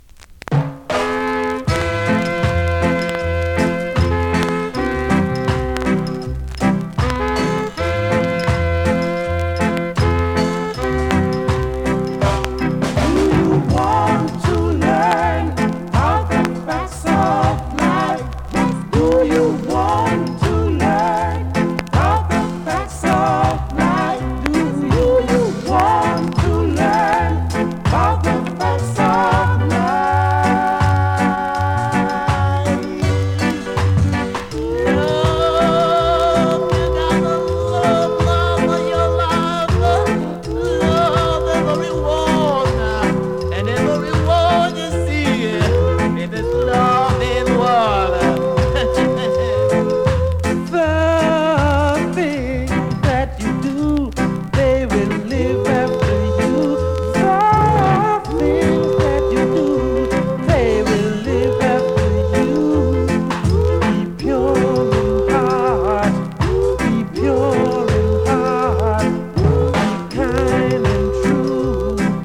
※前半に目立つスレ、プレイはOK
スリキズ、ノイズかなり少なめの